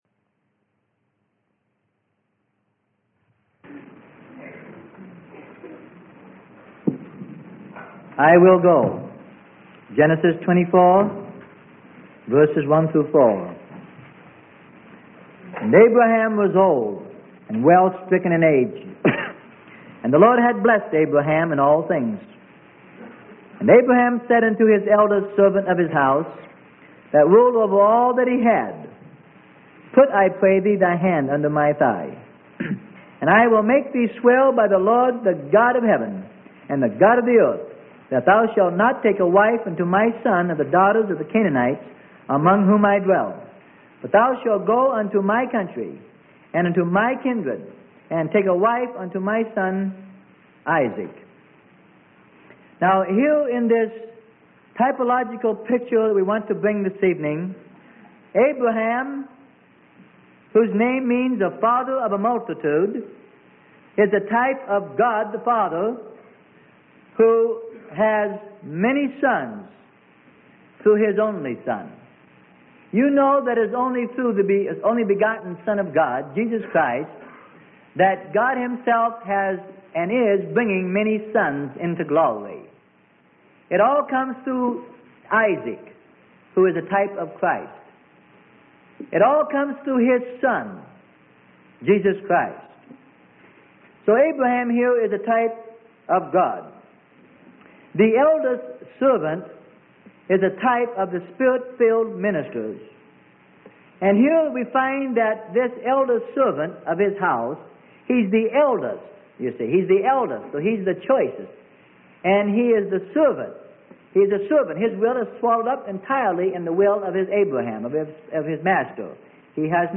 Sermon: I Will Go - Freely Given Online Library